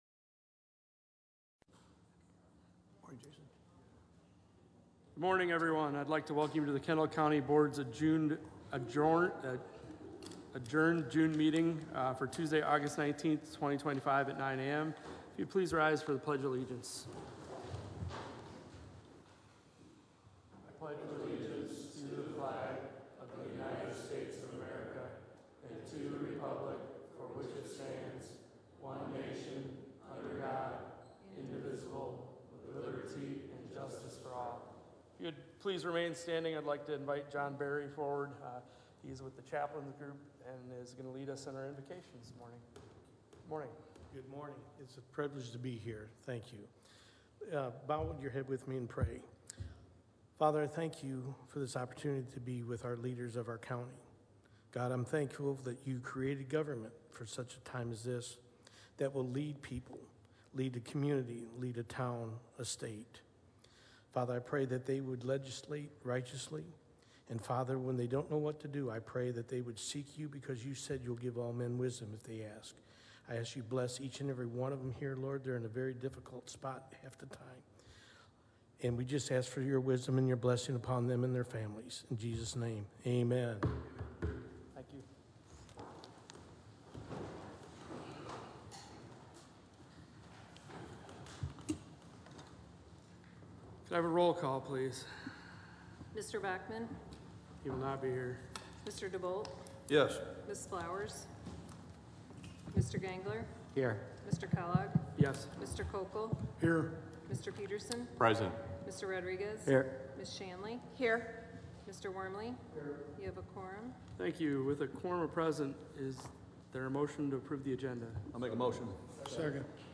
County Board Meeting
Location: Kendall County Historic Court House 110 West Madison Street, 3rd Floor Court Room Yorkville , Illinois 60560